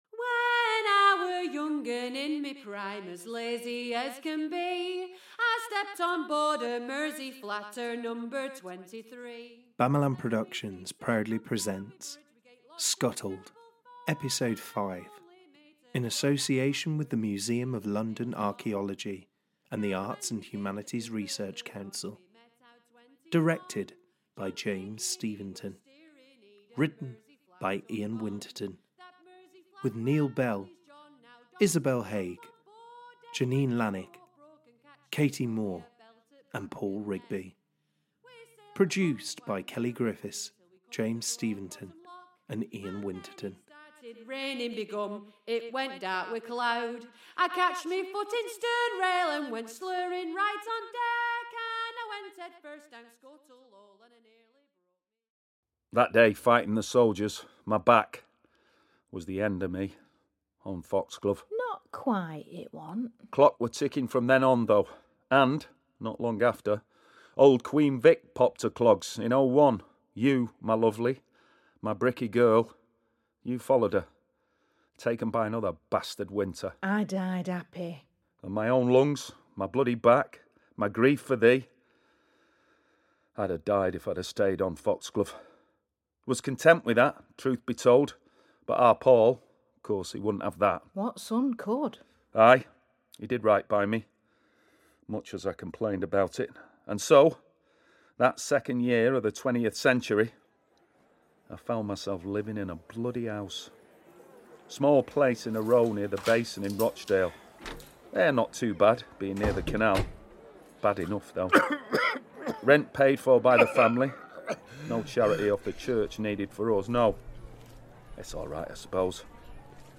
SCUTTLED is a full-cast, 5 episode audio drama following the Ashworths
Recorded at: Oscillate Studios (Manchester), Jungle Studios (Soho, London), and Voltalab (Rochdale).